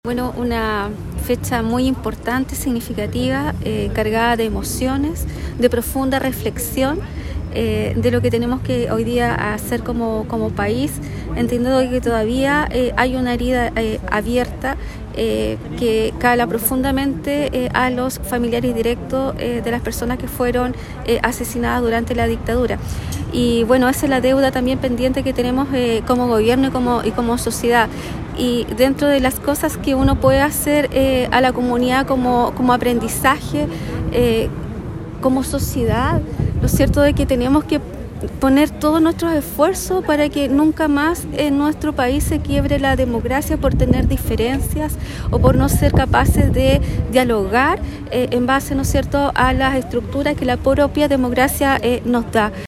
La Delegada Presidencial Provincial, Claudia Pailalef, indicó a 50 años del 11 de septiembre de 1973, existe una herida abierta por parte de las familias de las víctimas de la dictadura, por lo que reiteró la importancia reflexionar en torno a la importancia de la democracia.